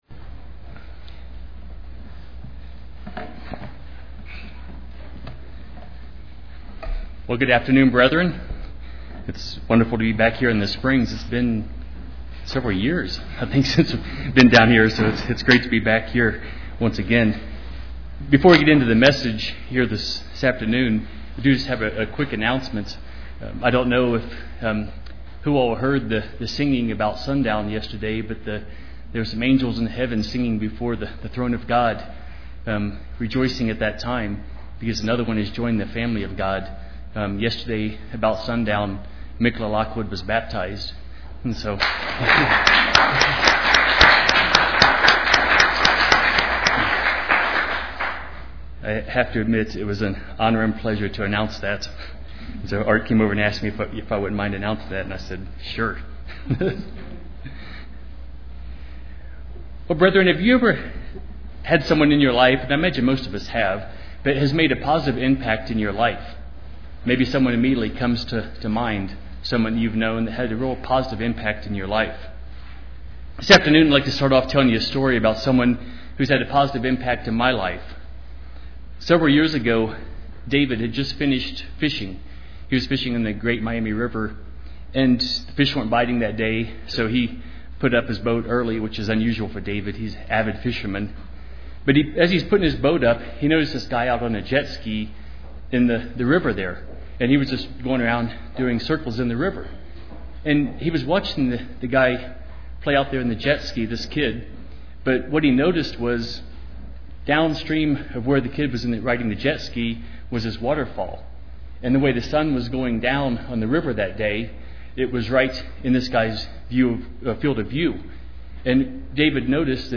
Most everyone at some point in their life will be affected by depression, either their own or someone else’s. People struggle with it today just as many in the Bible also did. In this sermon, we’ll look at how to deal with depression from a Biblical perspective.
Given in Colorado Springs, CO